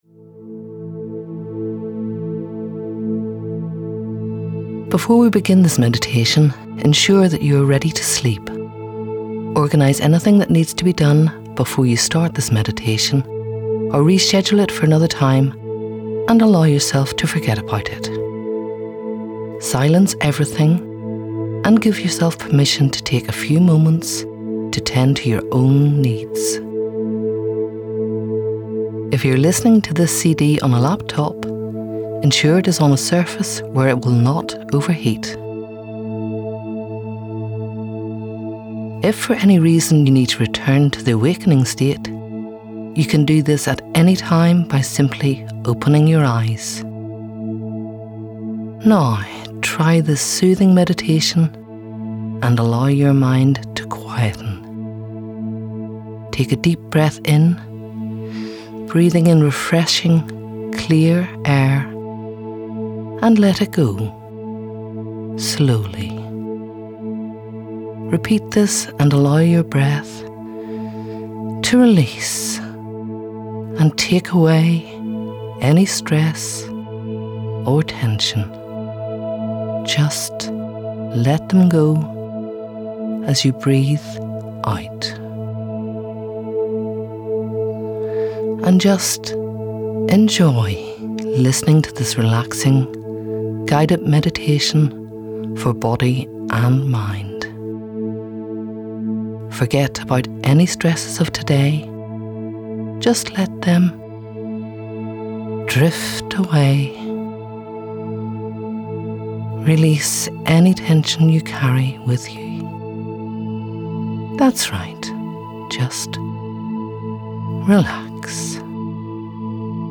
NICHS-Sleep-Meditation.mp3